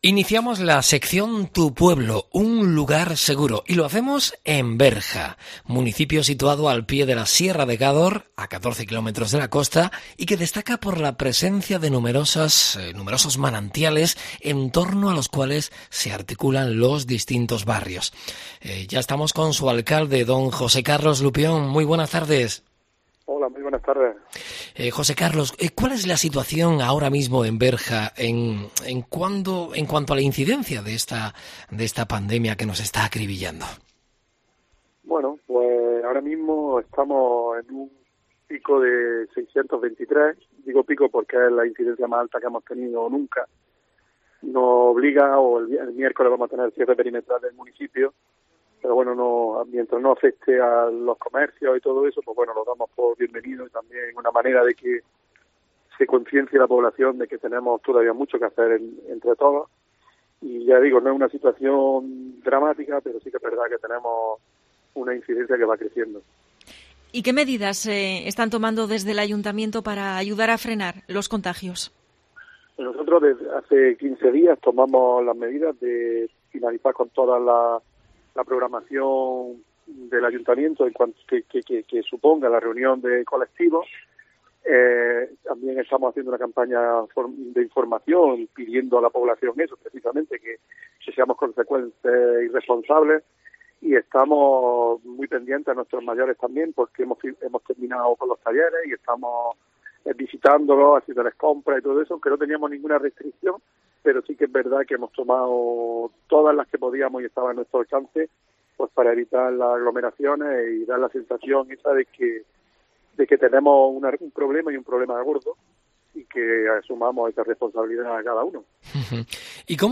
El alcalde de Berja (José Carlos Lupión) ha sido el primer invitado en el espacio 'Tu pueblo. Un lugar seguro', que COPE Almería dedica a los municipios en su lucha contra la COVID